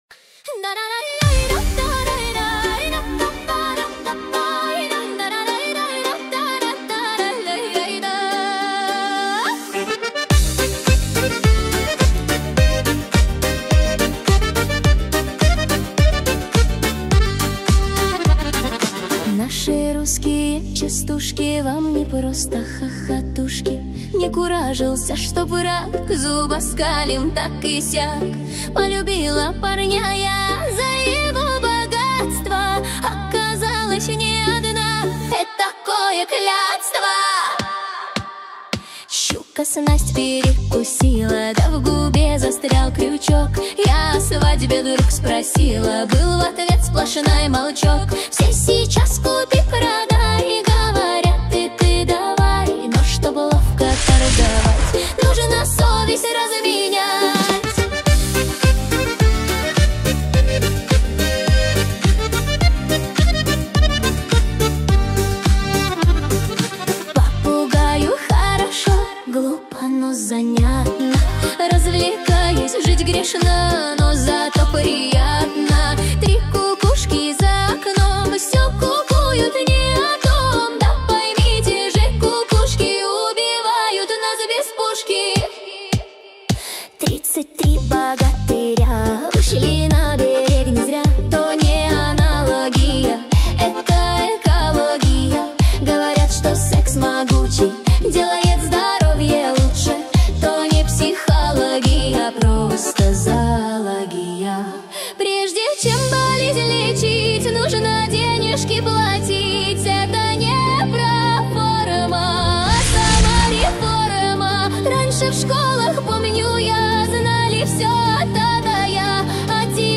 Поп музыка
Современные частушки